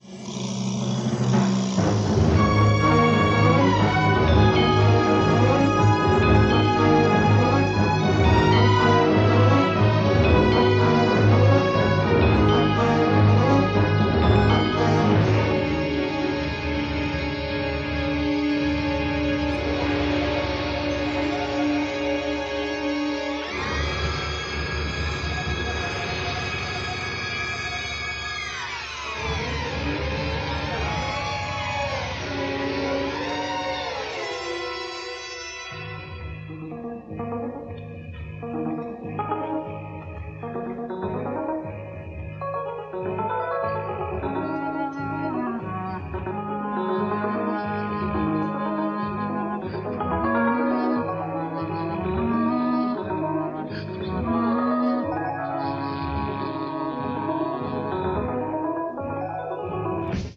innovative use of synthesizers combined with jazz elements